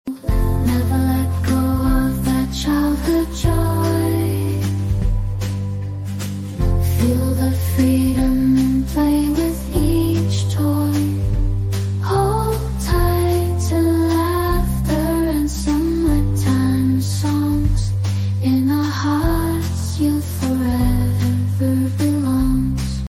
Her voice carries the laughter we thought we lost.